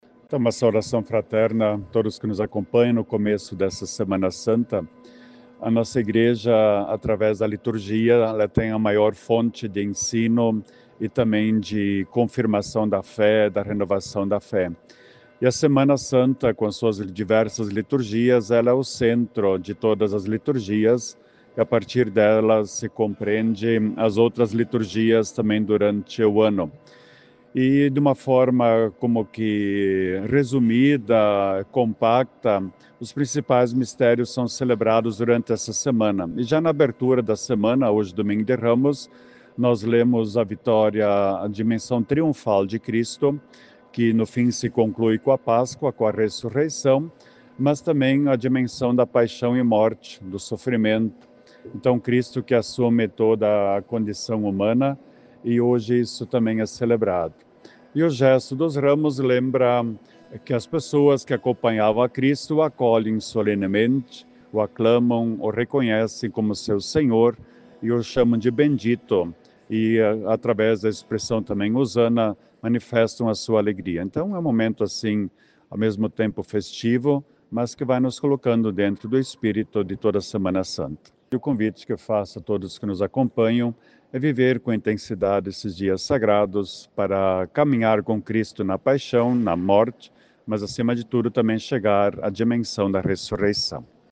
Aberta a Semana Santa: confira a programação na Catedral Arcebispo dom Rodolfo concede entrevista ao Grupo Planalto de Comunicação sobre a preparação
OUÇA A MENSAGEM DO ARCEBISPO DOM RODOLFO DURANTE O DOMINGO DE RAMOS: